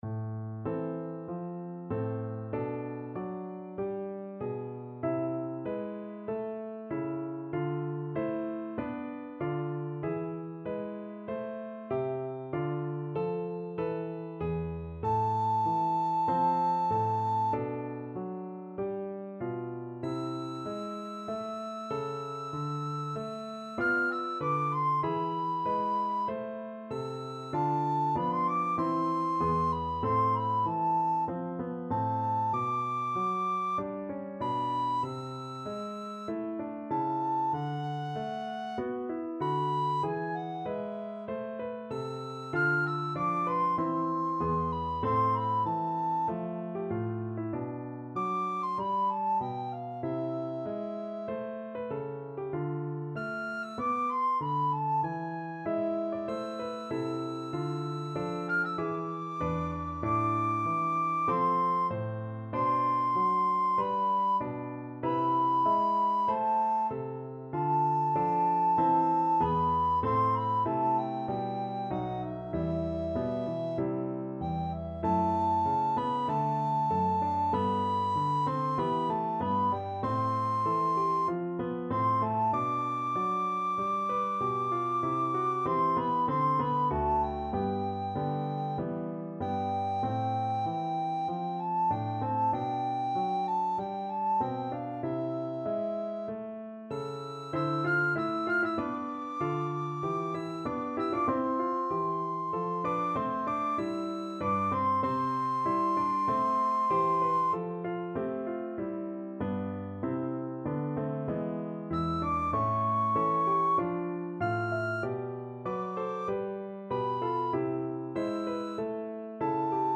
Recorder
A minor (Sounding Pitch) (View more A minor Music for Recorder )
4/4 (View more 4/4 Music)
E6-F7
Classical (View more Classical Recorder Music)